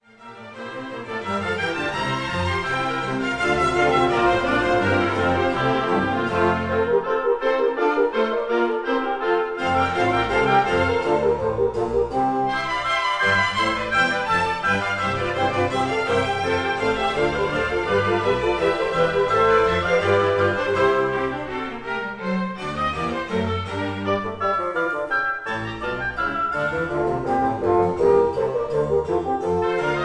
one instrument to a part